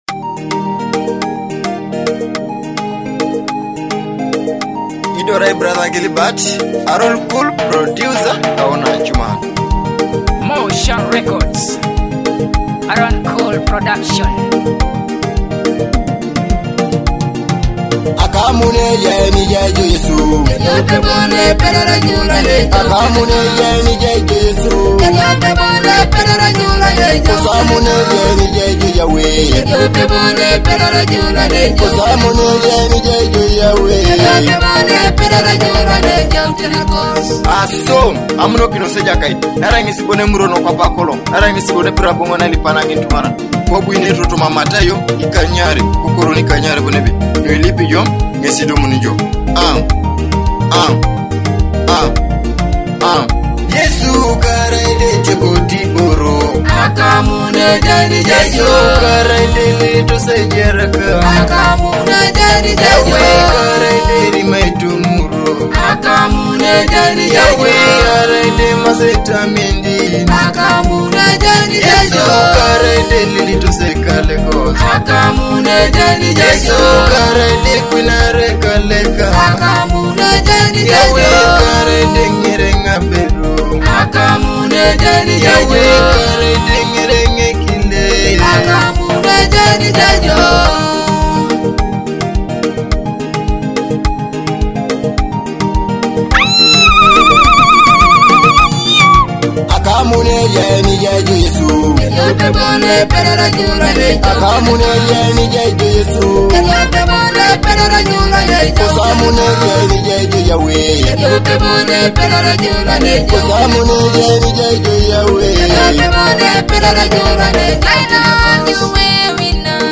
soul-stirring melodies
gospel masterpiece